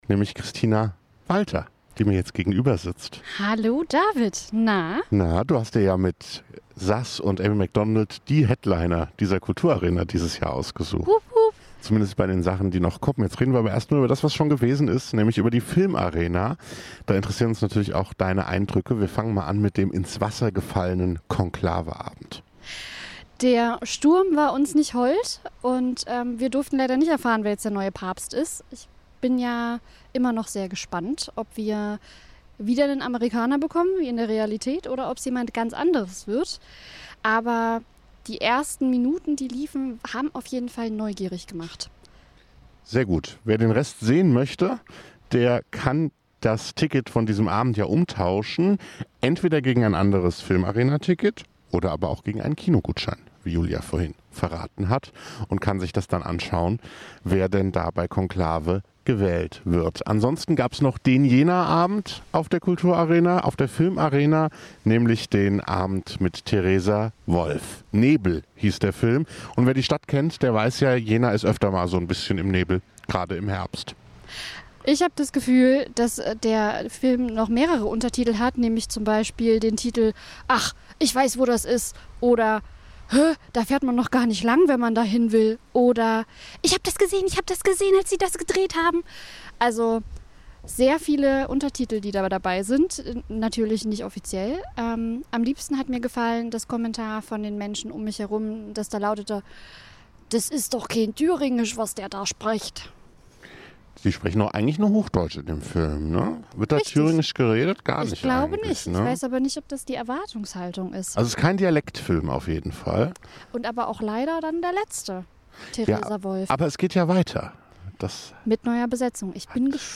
Liveberichterstattung vom Theatervorplatz.
rezension_filmarena_01.mp3